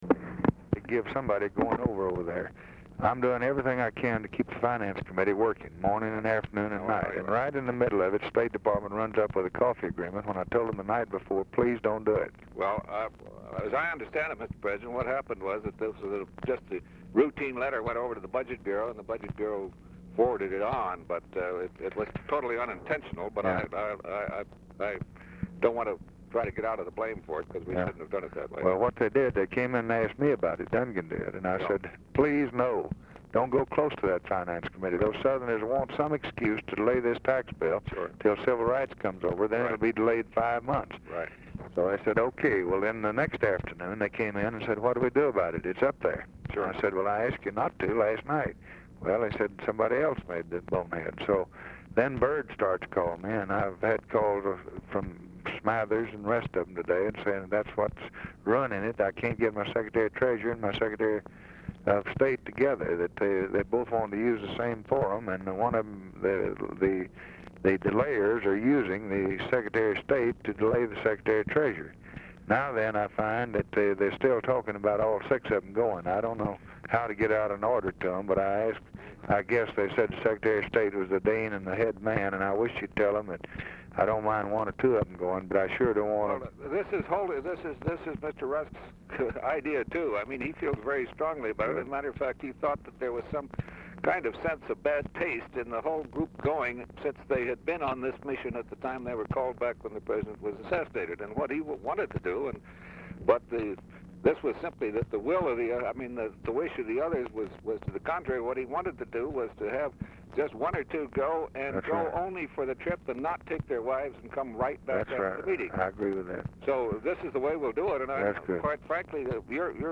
Telephone conversation # 479, sound recording, LBJ and GEORGE BALL, 12/13/1963, 5:45PM | Discover LBJ
RECORDING STARTS AFTER CONVERSATION HAS BEGUN
Format Dictation belt
Specific Item Type Telephone conversation